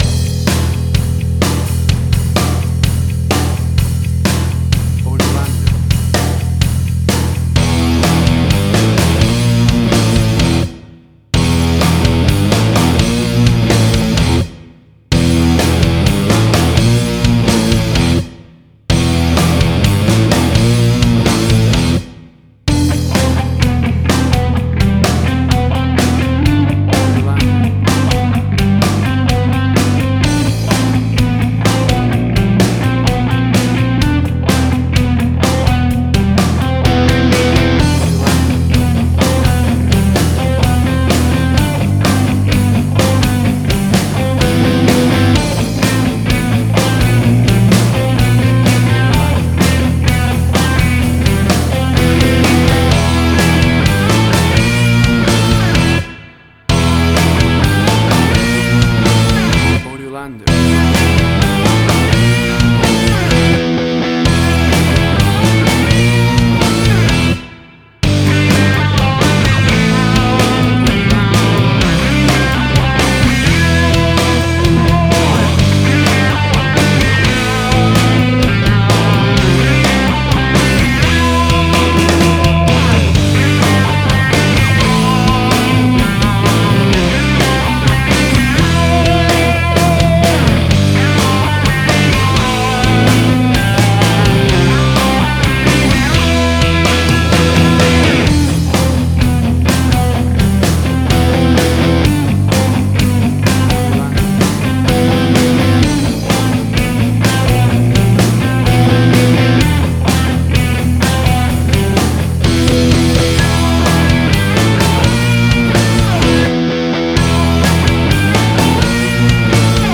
Hard Rock
Heavy Metal.
Tempo (BPM): 127